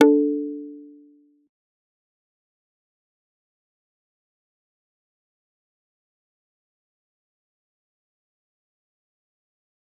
G_Kalimba-D4-pp.wav